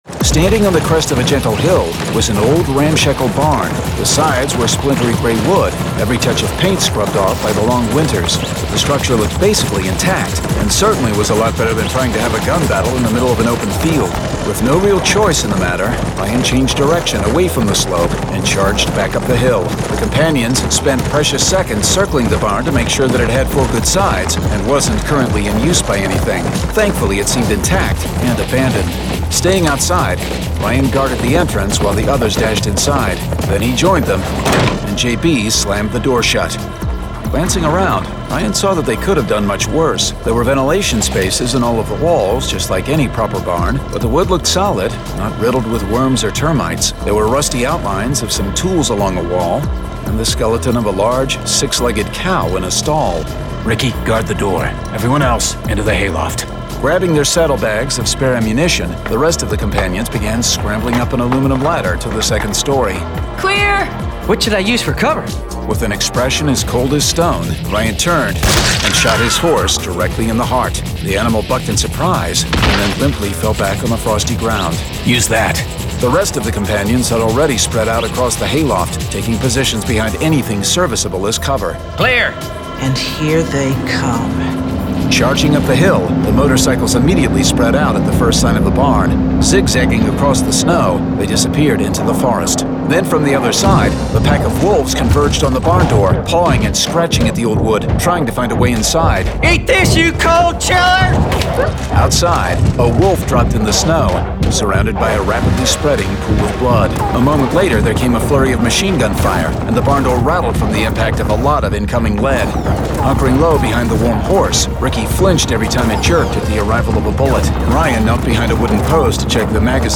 Full Cast. Cinematic Music. Sound Effects.
[Dramatized Adaptation]
Genre: Science Fiction